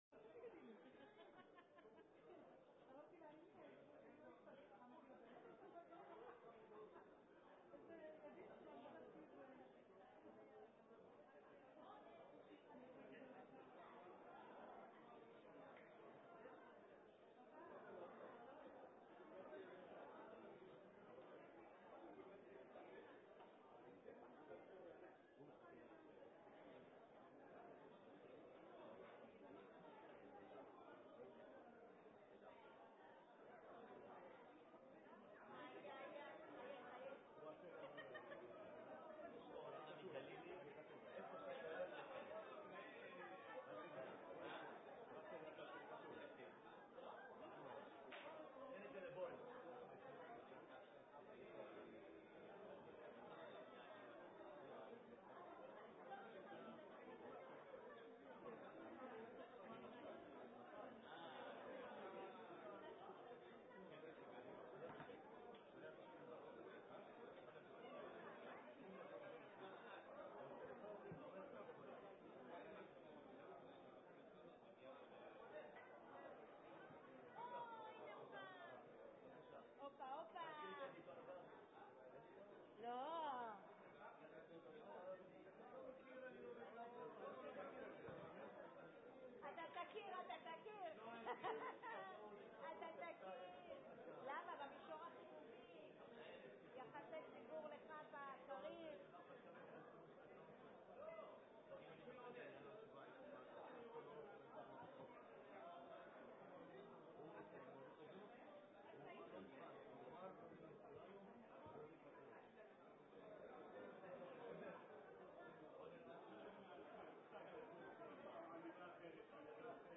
ישיבת מועצה מיוחדת 06-10-10.mp3